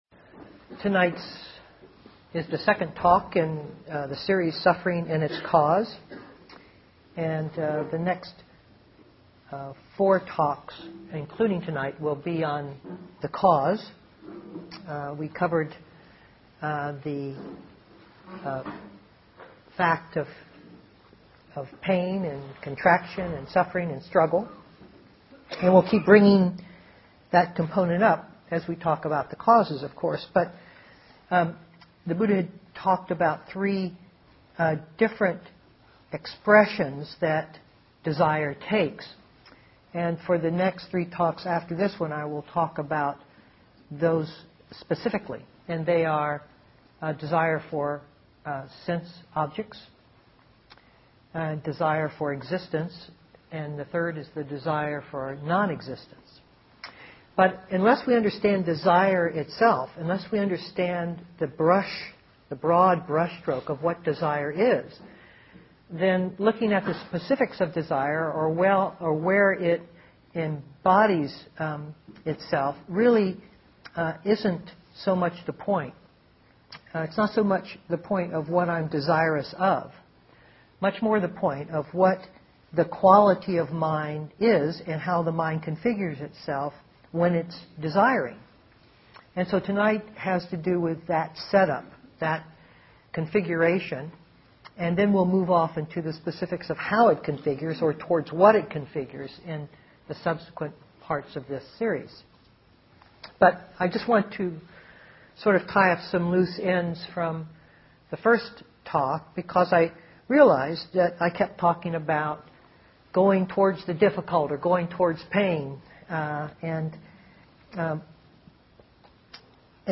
2006-01-31 Venue: Seattle Insight Meditation Center